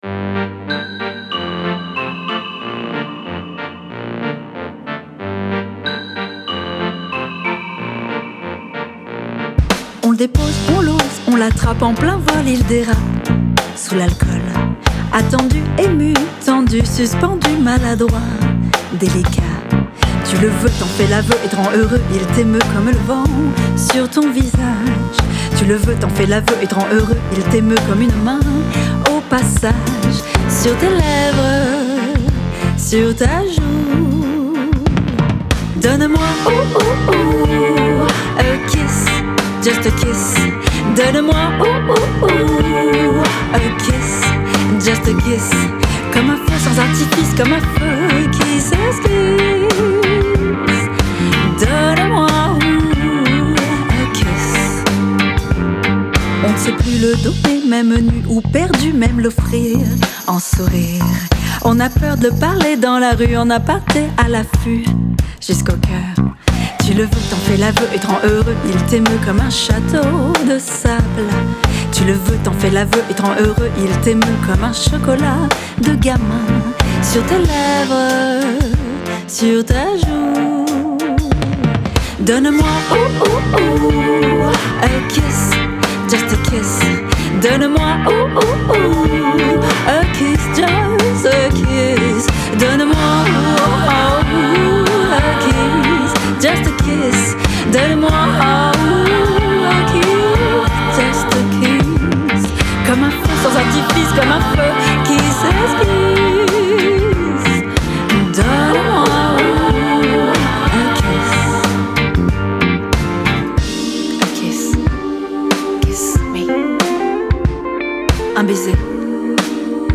Son univers : intime, sensuel, poétique, fragile.
De la Chanson Française Féline et Poétique.
piano
avec une voix chahutante, sensible et chaude